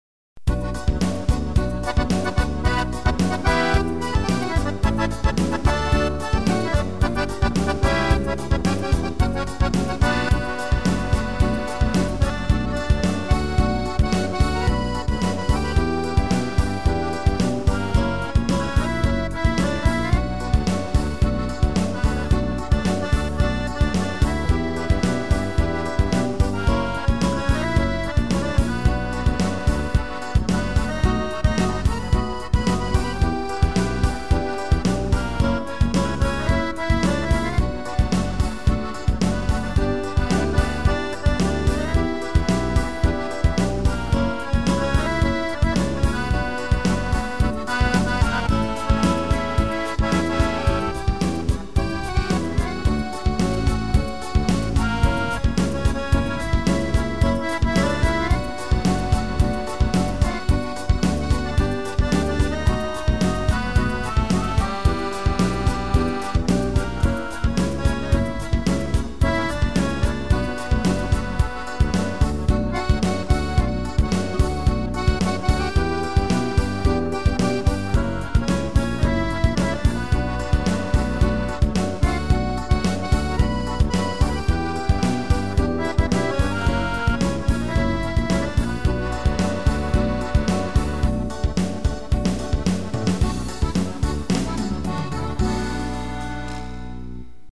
acordeão e flauta
(instrumental)